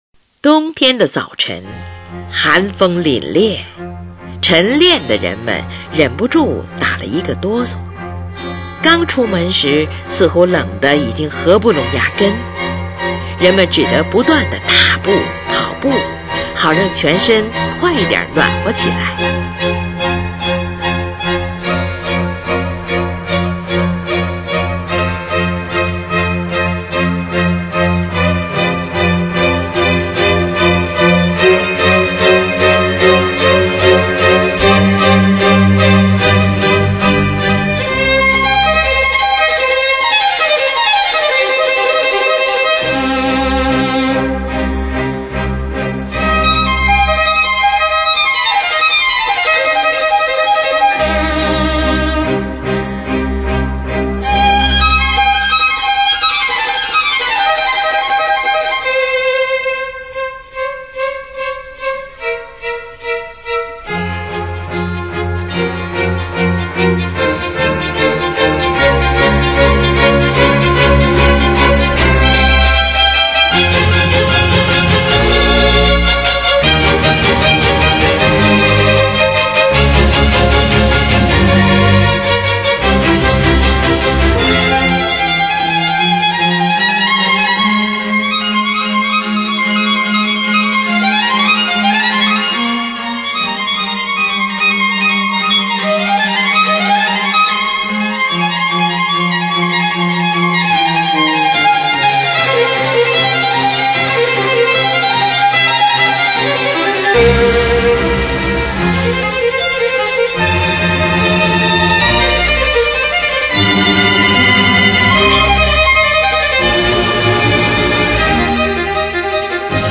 冬天--F小调